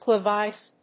クレビス